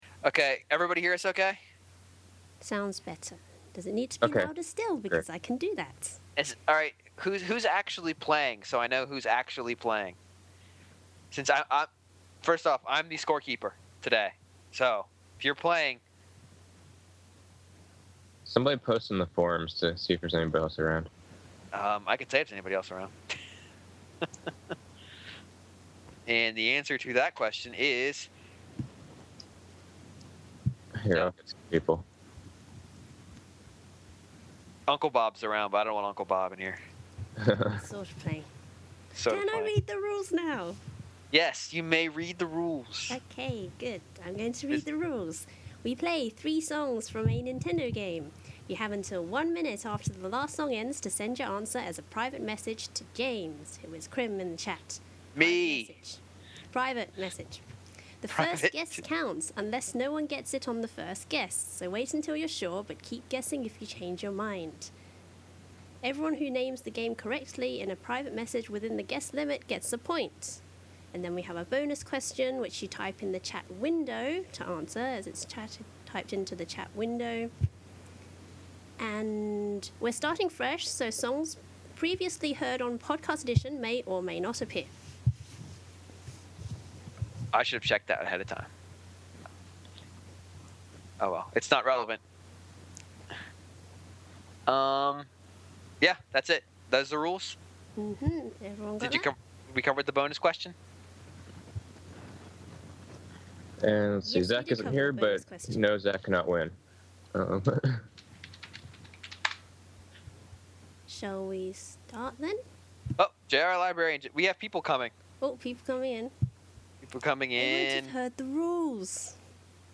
While there were fewer in attendance than the event last month, we still managed to pull off a music-filled evening.
It's not a great listen due to the nature of the show with long pauses, typing noises, etc. but the music tracks sound clear so here's a download for archive purposes.